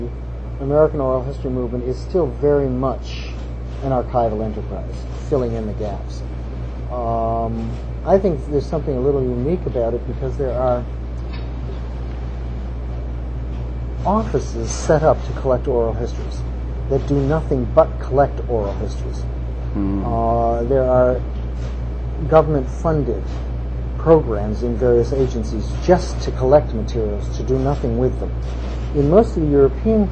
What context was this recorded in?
1 audio cassette